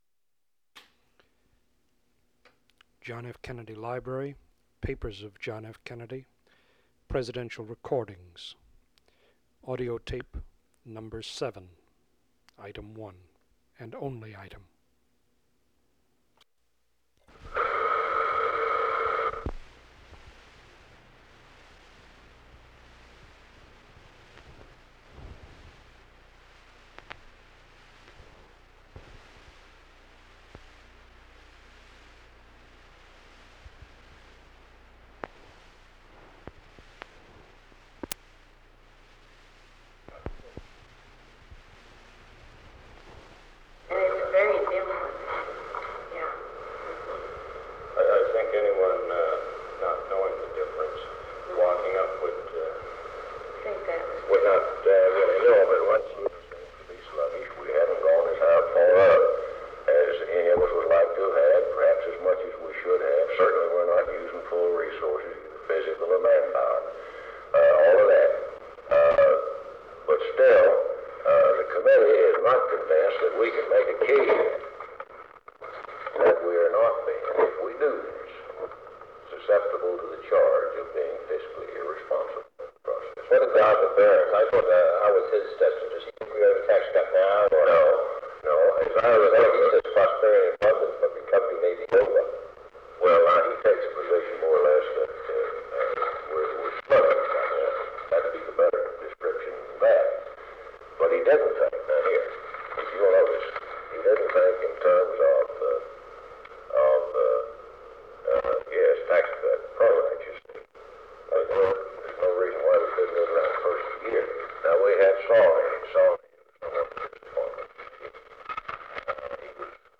Meeting with Wilbur Mills on the Tax Cut Proposal
Secret White House Tapes | John F. Kennedy Presidency Meeting with Wilbur Mills on the Tax Cut Proposal Rewind 10 seconds Play/Pause Fast-forward 10 seconds 0:00 Download audio Previous Meetings: Tape 121/A57.